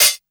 Closed Hats
hat 06.wav